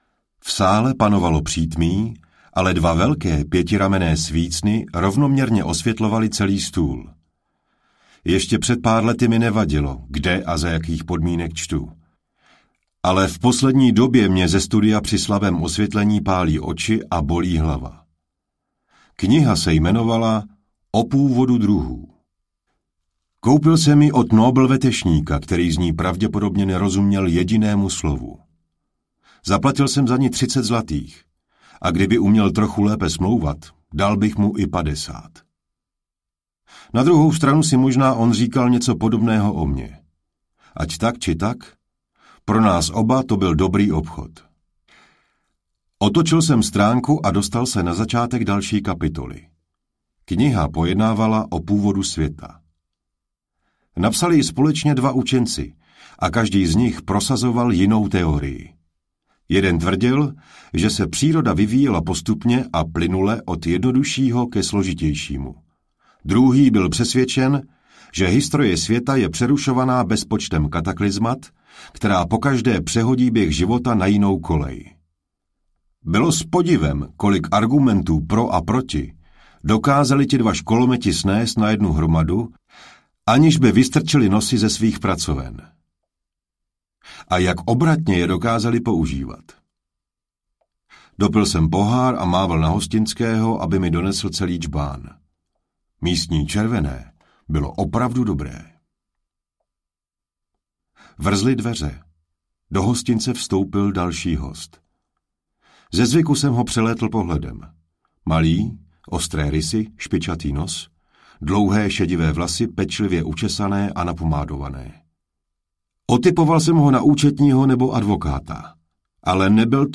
Na ostřích čepelí I. audiokniha
Ukázka z knihy